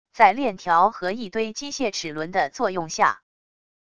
在链条和一堆机械齿轮的作用下wav音频